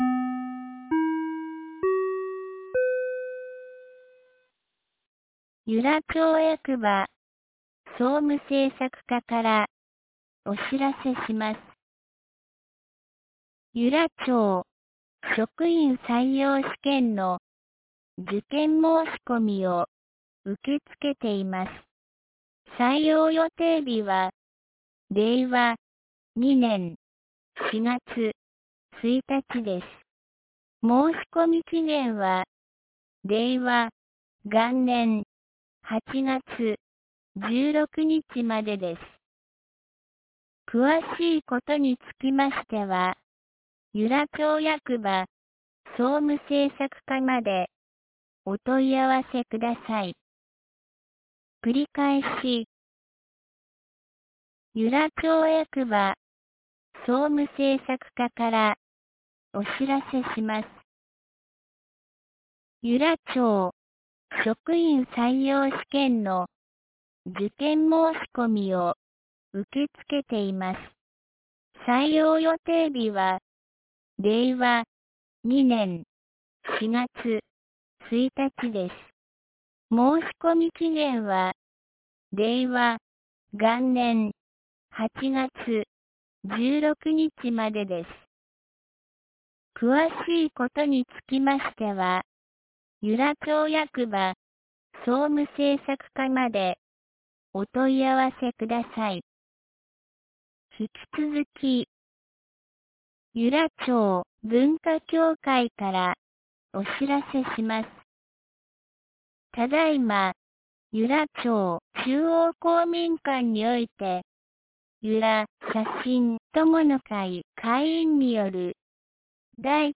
2019年08月12日 17時08分に、由良町より全地区へ放送がありました。
放送音声